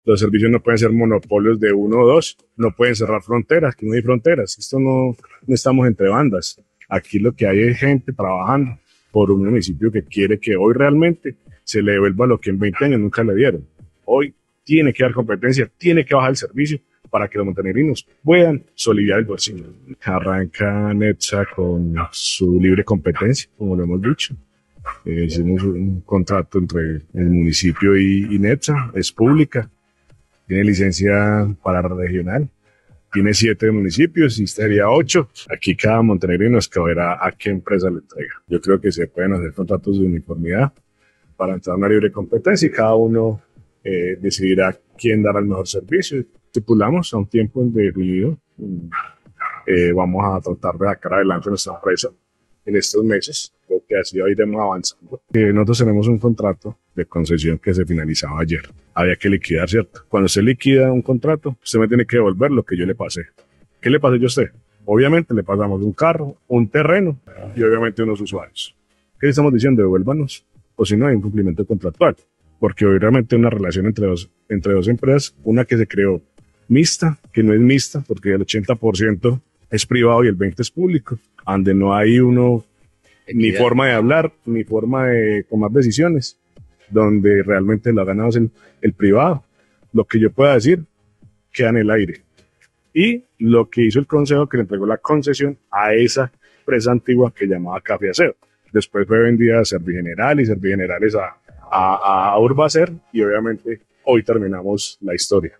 Alcalde de Montenegro
En una entrevista brindada al medio digital 180 grados, el alcalde de Montenegro Gustavo Pava fue claro que la comunidad ha solicitado trabajar con lo público y recuperar los servicios públicos.